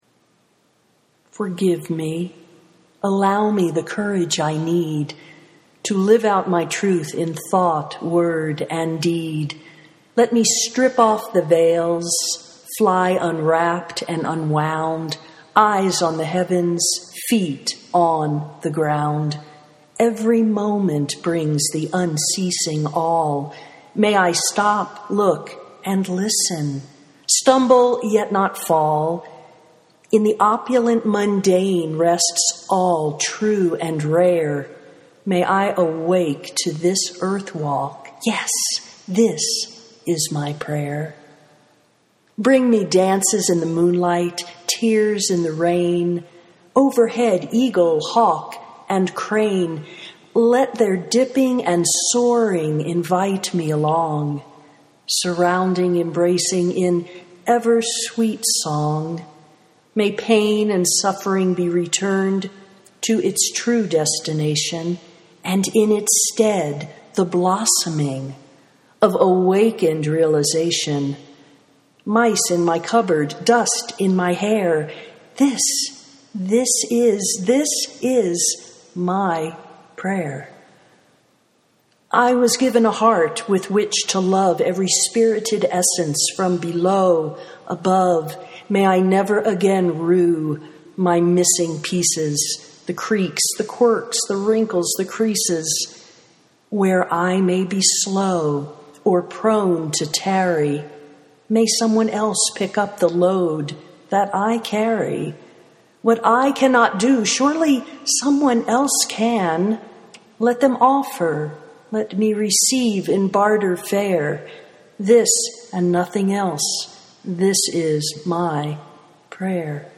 this is my prayer (audio poetry 2:48)